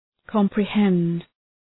Προφορά
{,kɒmprı’hend}